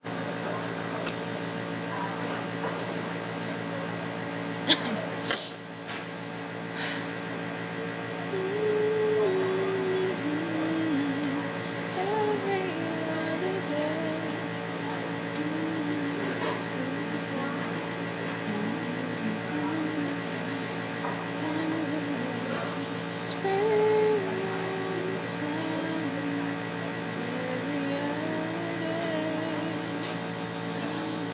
Buzzing – Hofstra Drama 20 – Sound for the Theatre
Field Recording #4
Sounds Heard: Heater, chattering, humming, clanging/movement